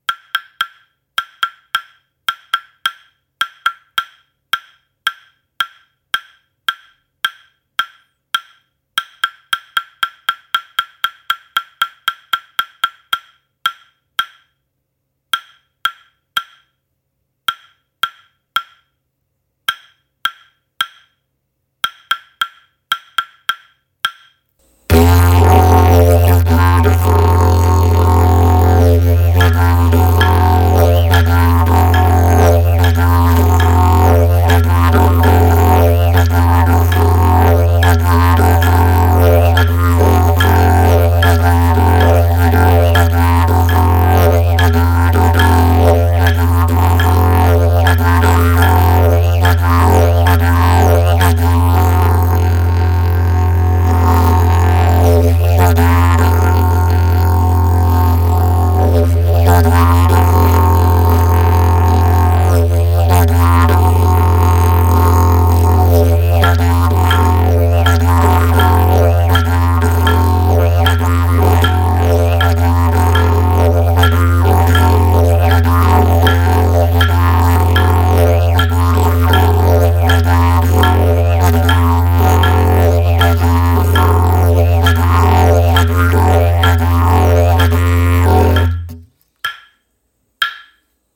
このMingaから2セット作れそうなドデカく分厚く、重厚でやわらかなサウンド！